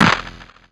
IFHY Clap.wav